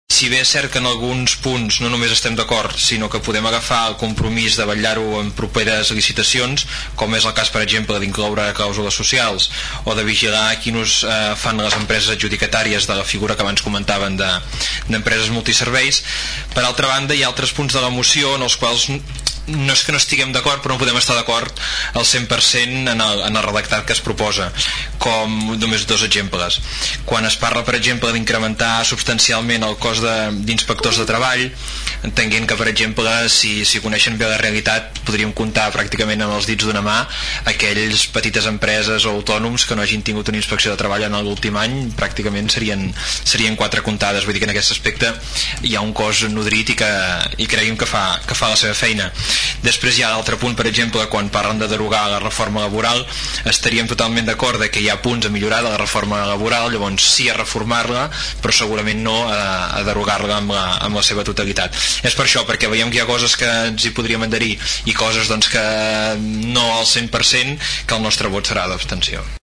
El ple de l’Ajuntament de Tordera aprova una moció al voltant de les empreses multiserveis
Pel que fa al PDeCAT, el regidor Marçal Vilajeliu va posar de manifest que la moció és molt àmplia i que no poden estar d’acord amb la totalitat del text, com quan parla dels inspectors de treball i de la derogació de la reforma laboral.